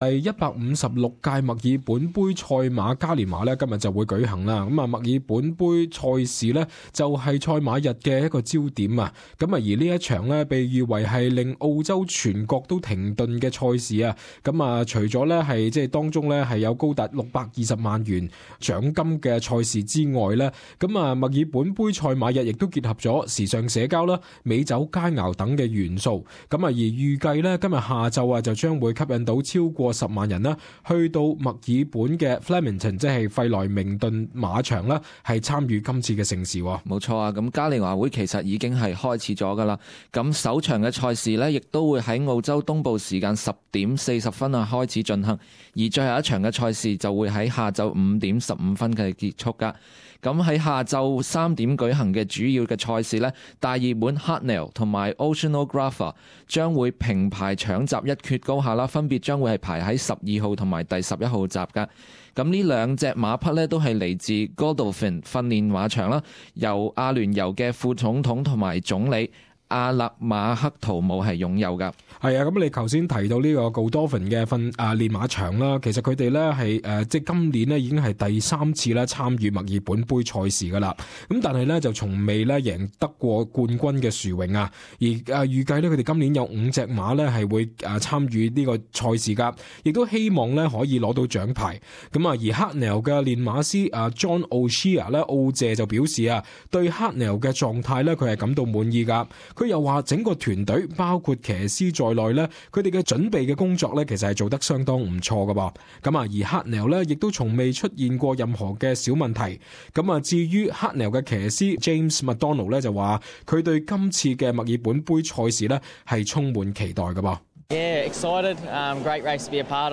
【時事報導】 「令澳洲全國停頓」的墨爾本盃